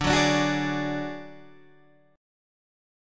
D Major Flat 5th